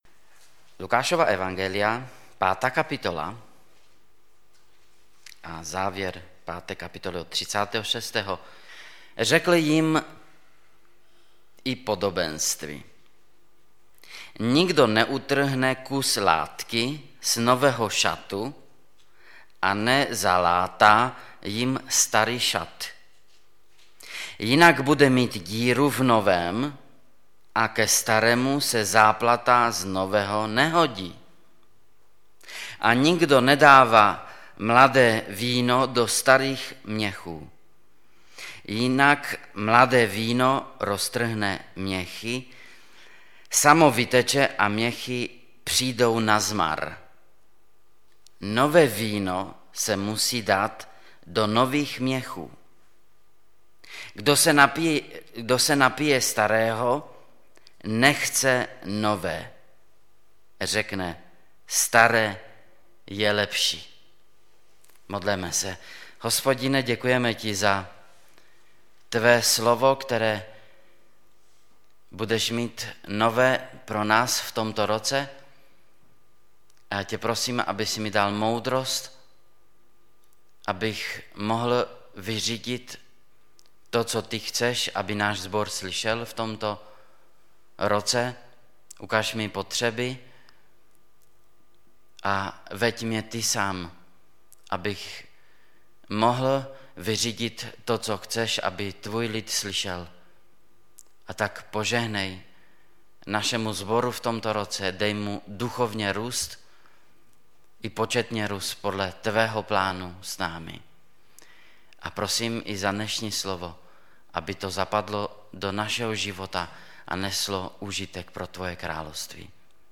Kázání MP3